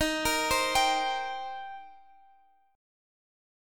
Listen to D#6 strummed